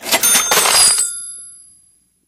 bp_use_curency_buy_01.ogg